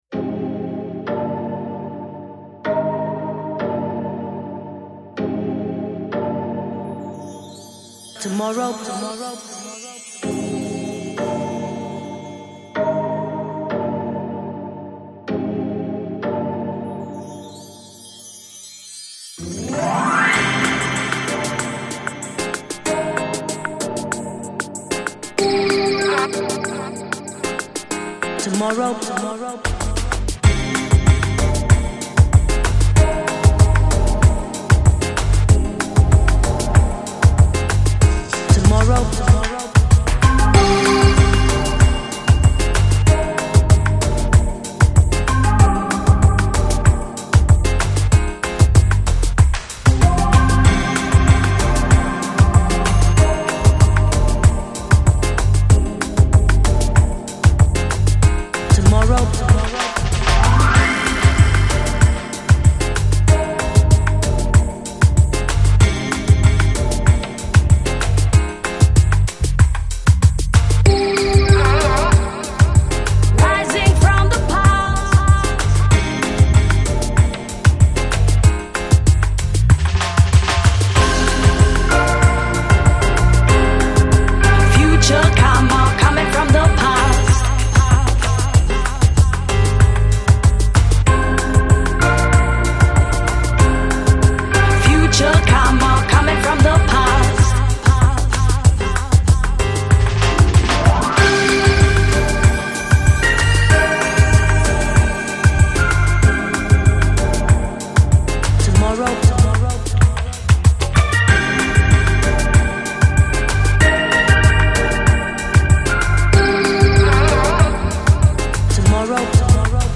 Жанр: Downtempo, Lounge, Chillout